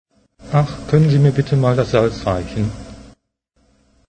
The couple at the next table are speaking German. One of them turns to you and says something which sounds like this: